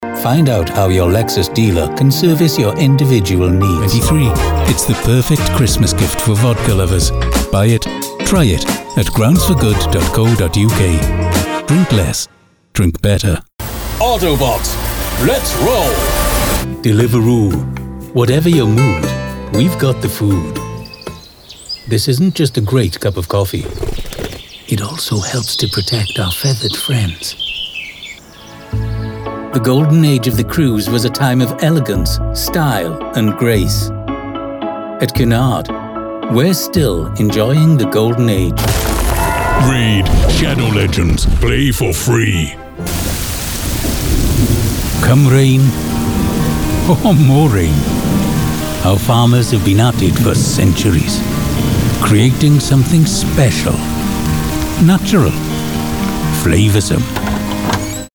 Englisch (Britisch)
Senior
Vertrauenswürdig
Warm